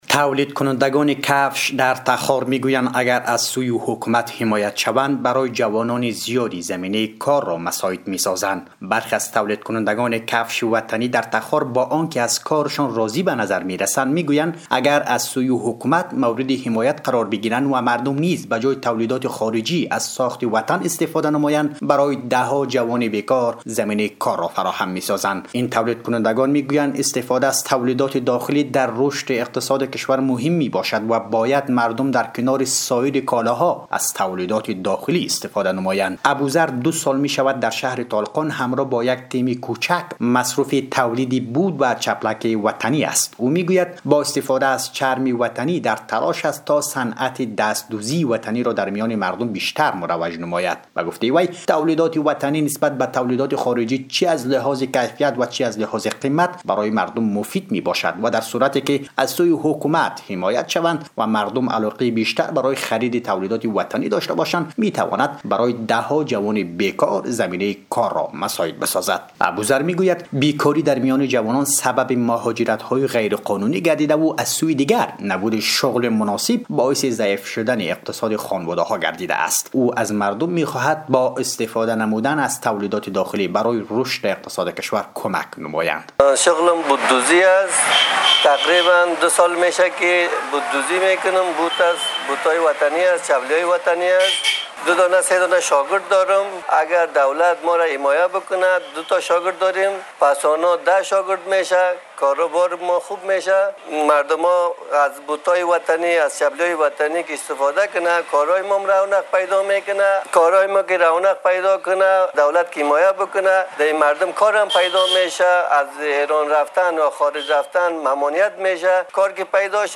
رادیو دری